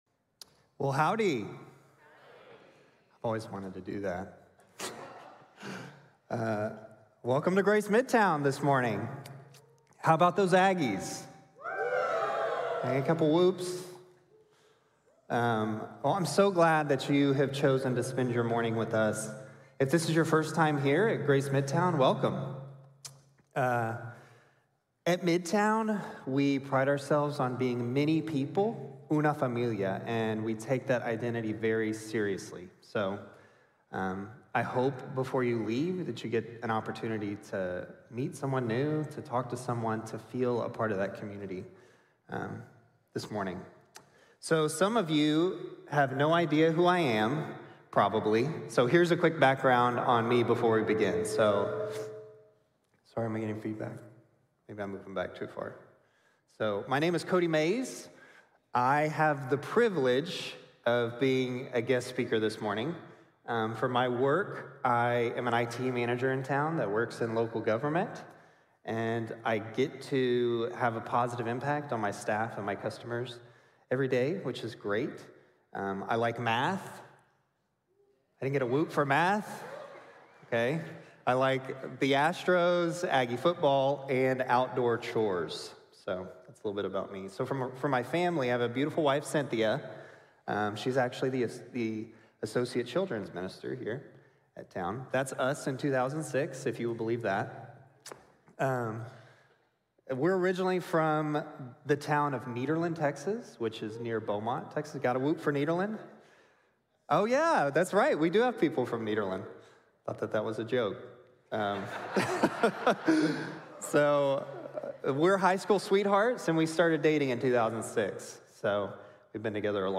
Finding Purpose in Persecution | Sermon | Grace Bible Church